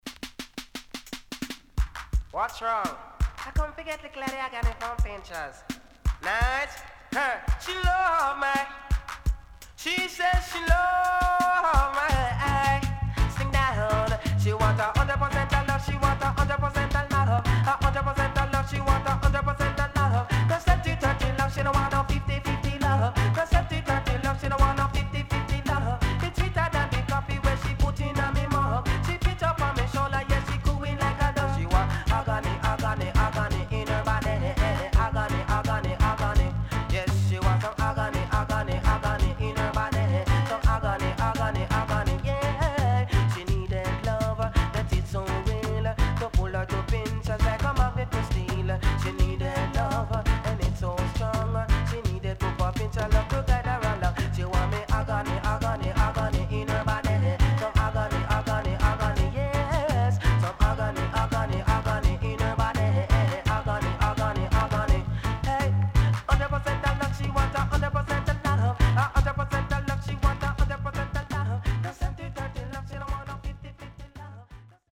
HOME > LP [DANCEHALL]
SIDE A:少しチリノイズ入りますが良好です。